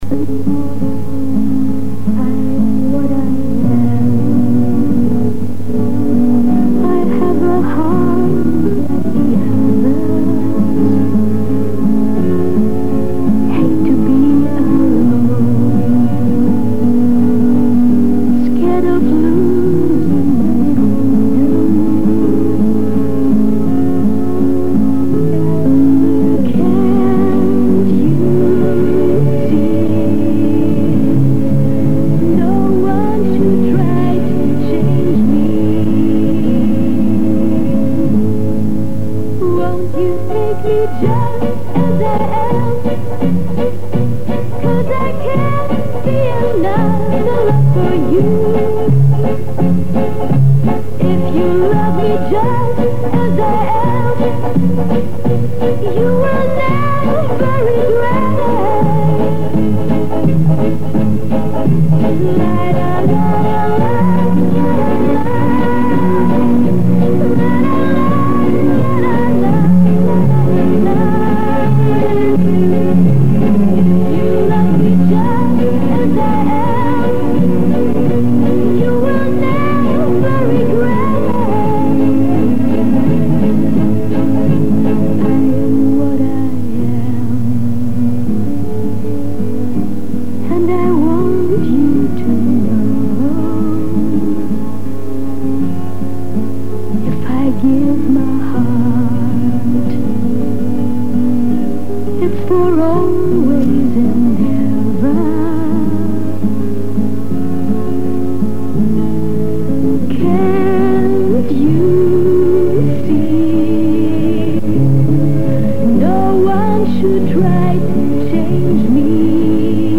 05 - SOUL